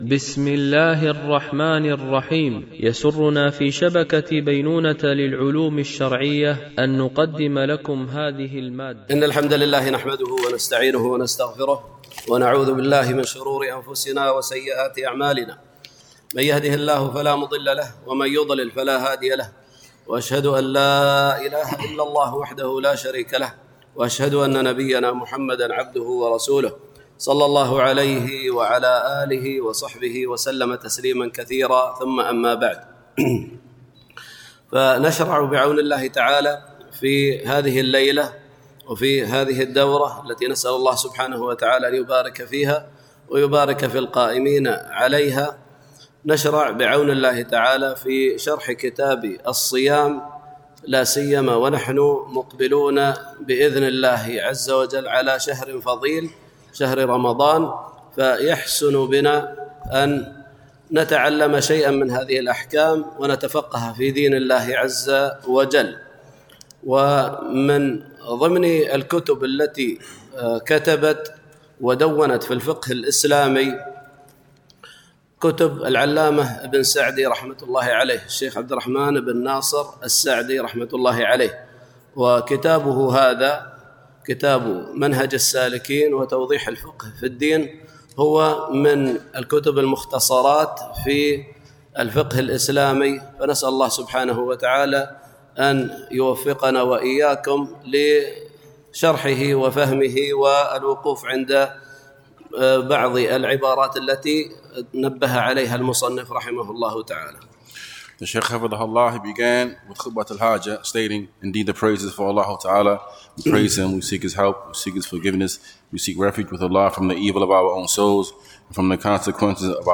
دورة علمية مترجمة للغة الإنجليزية، لمجموعة من المشايخ، بمسجد أم المؤمنين عائشة رضي الله عنها